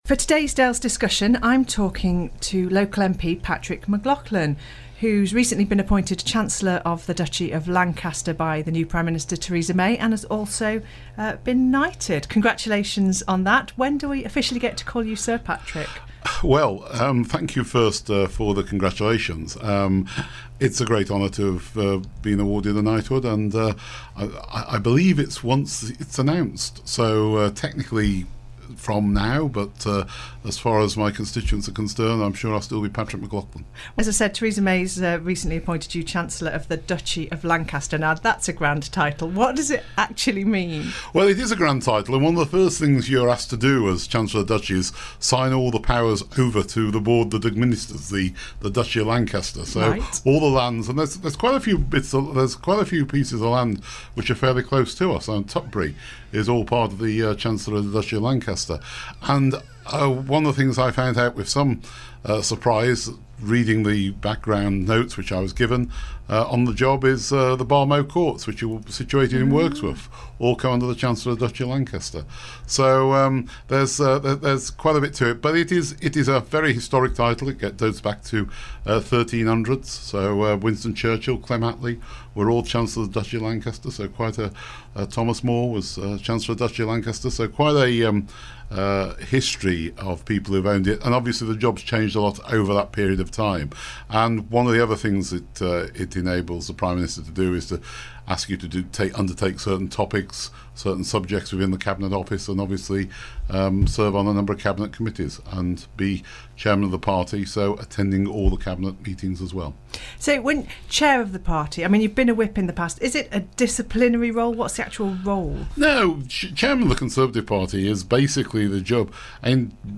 In the second part of this interview to be broadcast tomorrow (Wednesday) we'll also talk about the EU referendum and Patrick's early life as a miner.